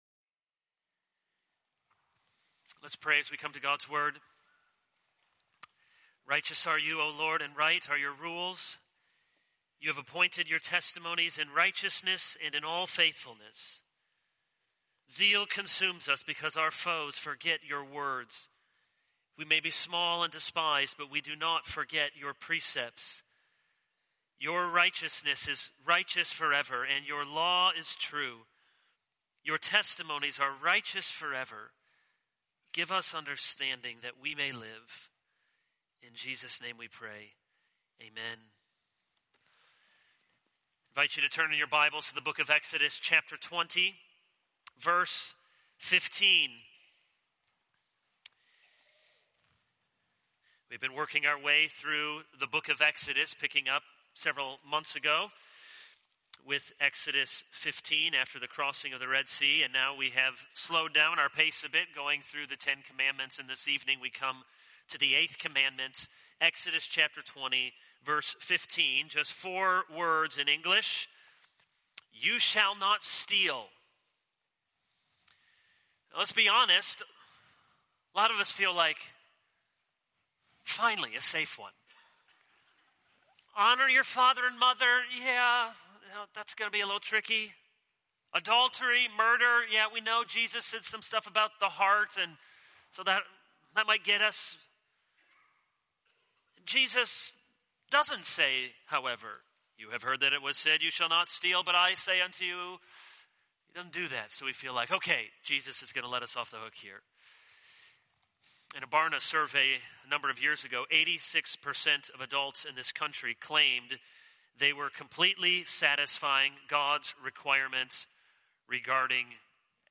This is a sermon on Exodus 20:15.